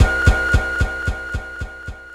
Track 15 - Flute Delay.wav